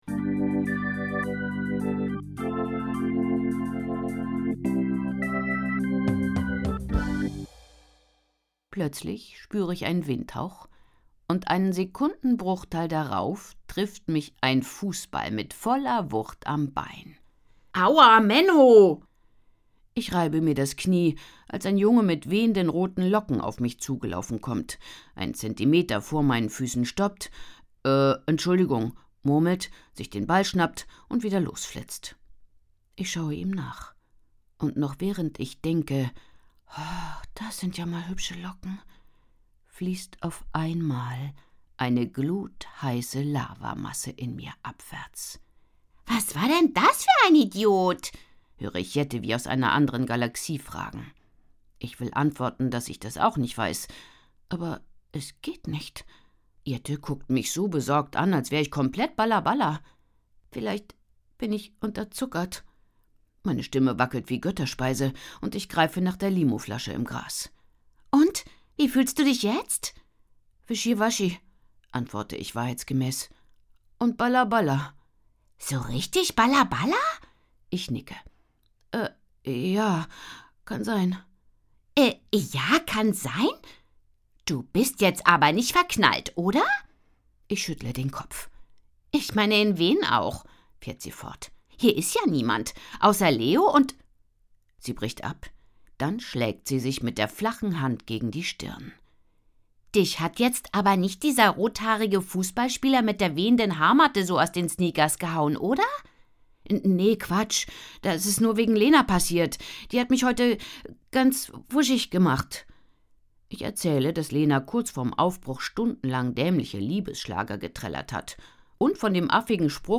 Hörbuch, 2 CDs, ca. 158 Minuten